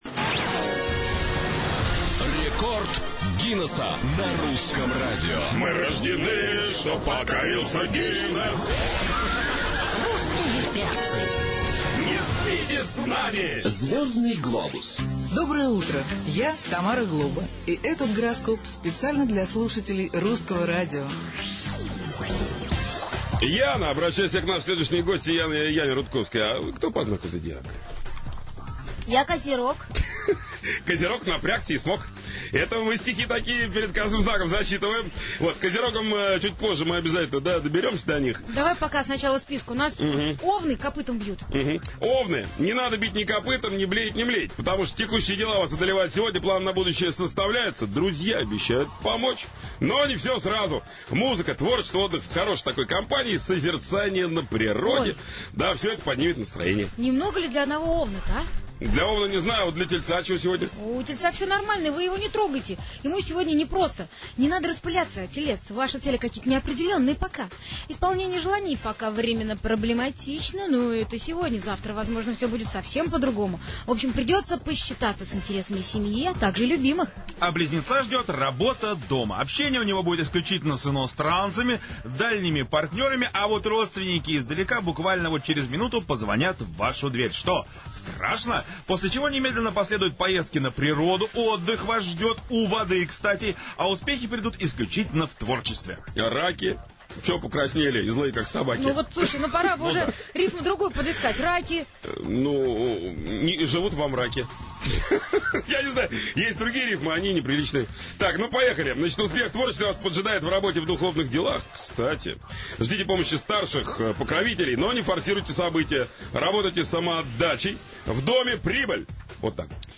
Рекорд Гиннесcа на "Русском радио" vol.1. Запись эфира.
Утреннее шоу "Русские перцы" устанавливает рекорд по непрерывному шоу в прямом эфире. Идут первые сутки.
| Теги: русские перцы, рекорд Гиннесса, запись эфира, диджей, Русское Радио, утреннее шоу